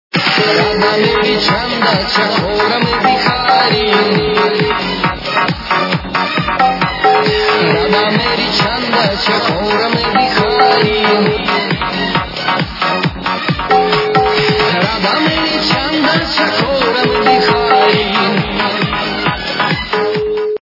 народные
При заказе вы получаете реалтон без искажений.